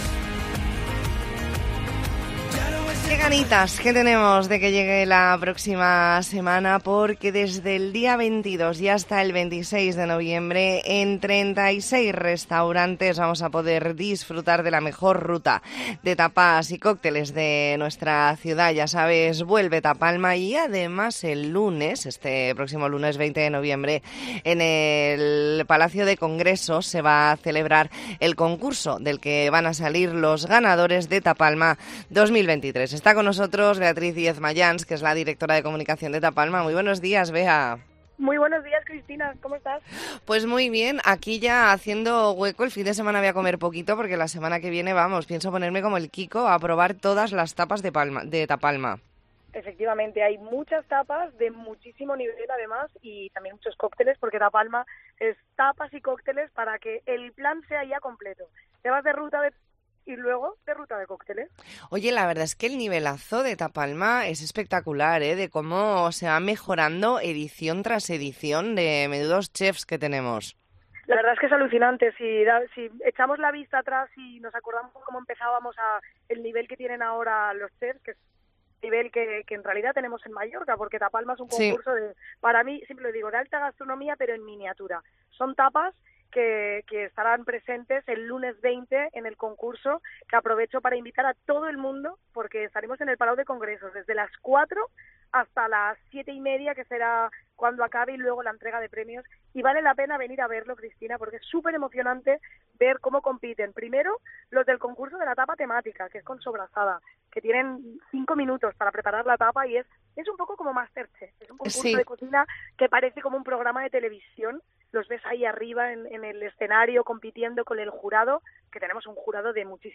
ntrevista en La Mañana en COPE Más Mallorca, viernes 17 de noviembre de 2023.